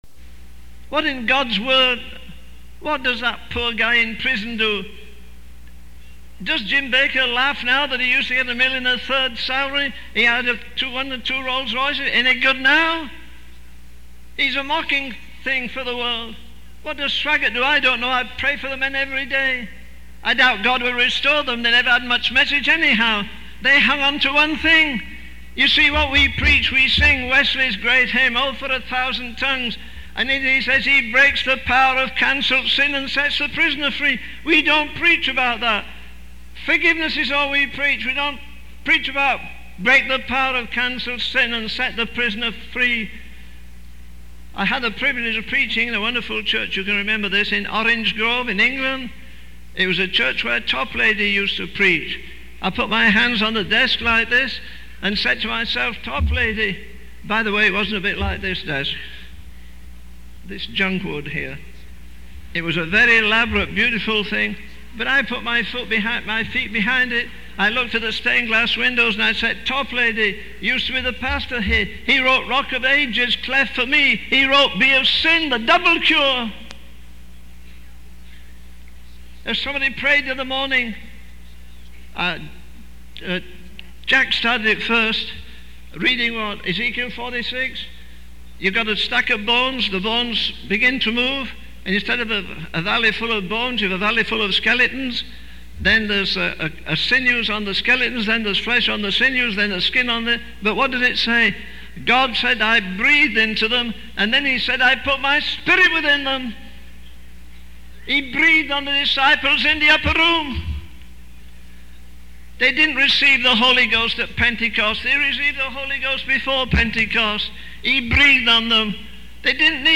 In this sermon, the speaker discusses the importance of dying to oneself and surrendering to God's plans. He emphasizes that true success comes from God's triumph and that He will raise up those who are obedient to Him. The speaker also highlights the need for holy anger and jealousy for God's glory, as seen in the example of Samson.